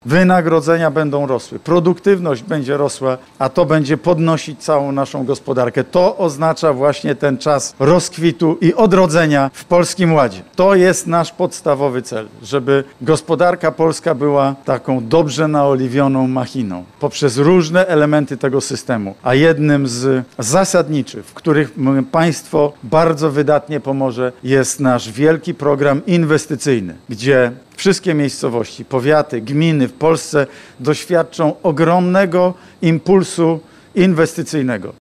Chcemy rozwijać działalność eksportową i produkcyjną polskich firm – powiedział premier Mateusz Morawiecki podczas dzisiejszej (17.05) wizyty w Stężycy w firmie JMP Flowers. Szef rządu rozpoczął trasę po Polsce, podczas której promuje „Polski Ład” – nowy program społeczno-gospodarczy Zjednoczonej Prawicy na okres pocovidowy.